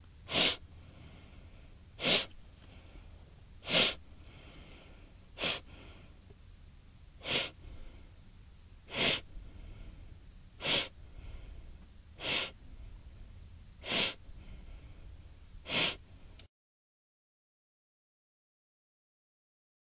Descarga de Sonidos mp3 Gratis: snif olfatear.
snif-1.mp3